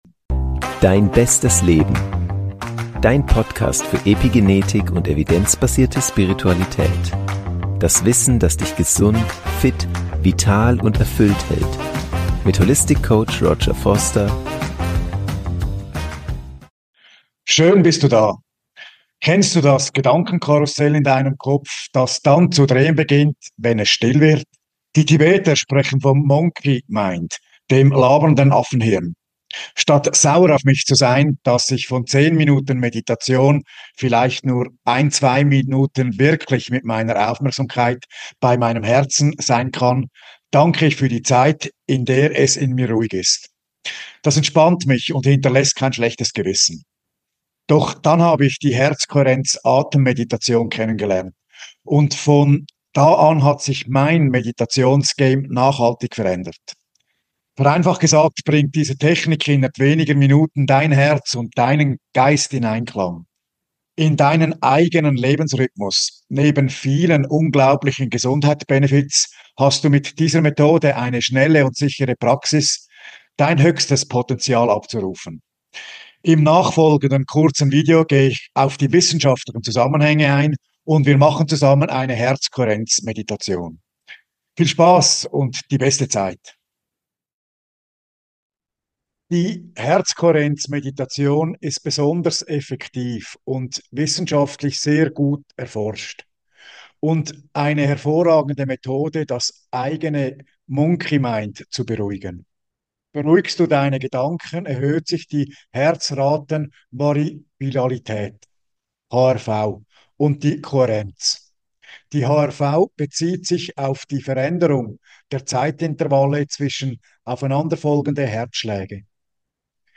Mit dieser kraftvollen Meditation mit binauralen Beats kannst Du die Kohärenz zwischen Kopf und Herz herstellen.